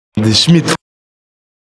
schmidt_prononciation.mp3